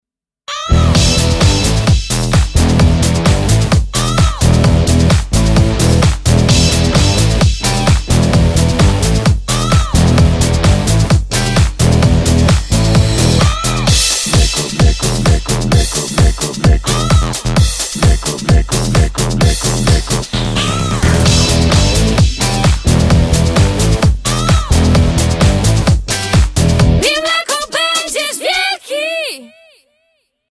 spot radiowy